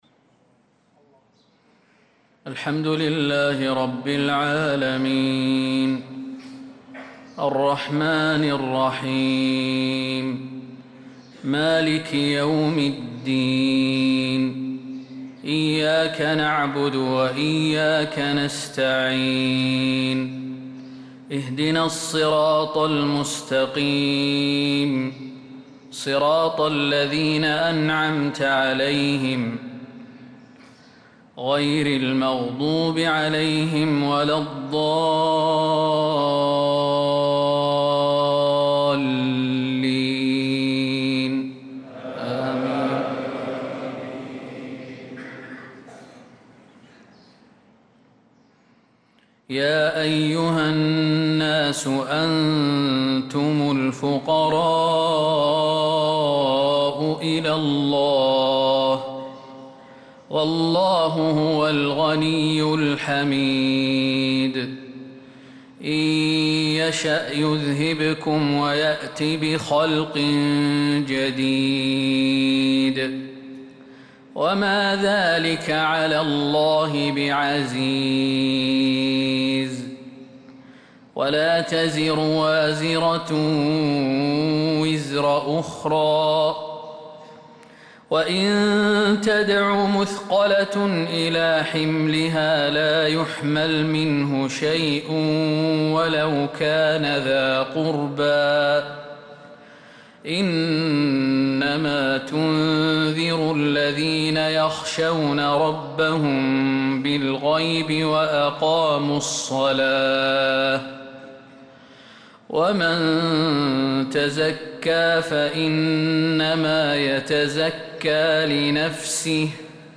صلاة الفجر للقارئ خالد المهنا 3 جمادي الأول 1446 هـ